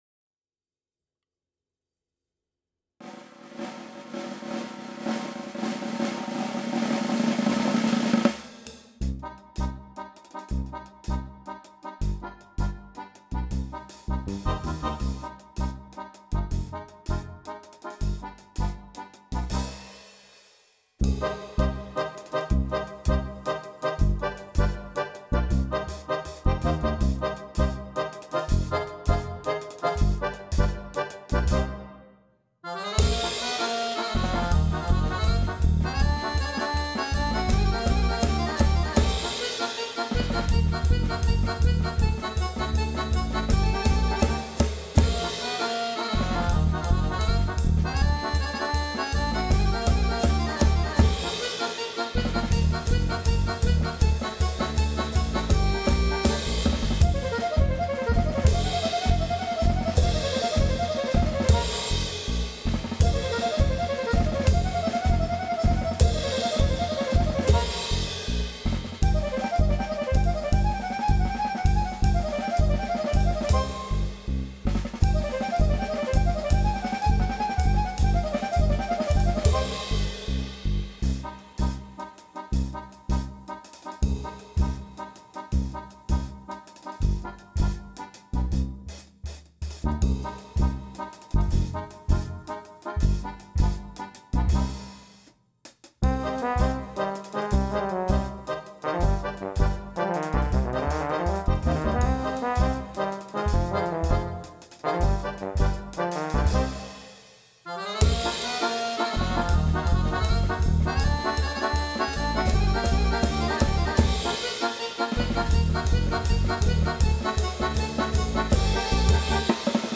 Un Freilach, danse klezmer joyeuse, écrite en remerciement à toutes celles et ceux qui me souhaitent mon anniversaire !
Pour quatuor de clarinettes, accordéon, basse, batterie.
the-freilach-of-april-26-rythmique-seule.wav